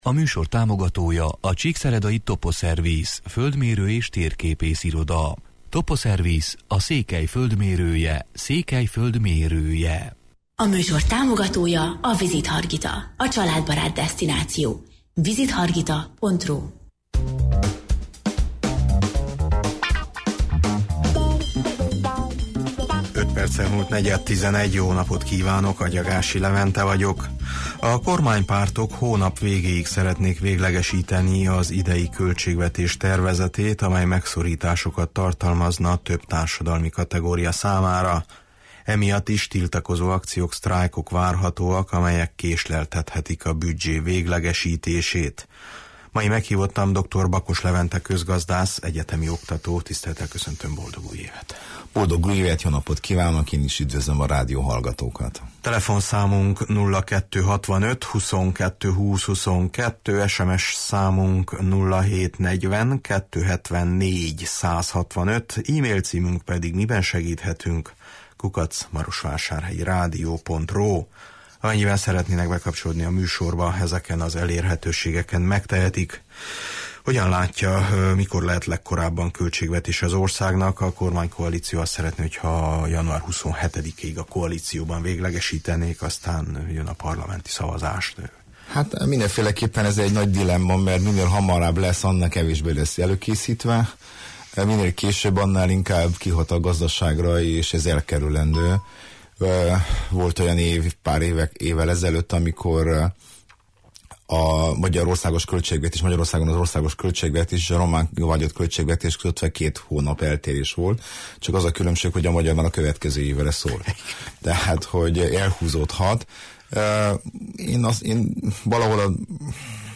Adóemelés nélkül állítják össze az állami költségvetést, de az adóhivatal ambiciózusabb többletbevételekkel fog előrukkolni, jelentette ki Tánczos Barna pénzügyminiszter, aki szerint tovább csökkentik a kiadásokat, és adóreformot készítenek elő az idei büdzsé elfogadása után. A kormánypártok a hónap végéig szeretnék véglegesíteni az idei költségvetés tervezetét. Az államháztartásról, adókról, a tervezett reformról, véghezvitelének esélyeiről beszélgetünk mai műsorunkban.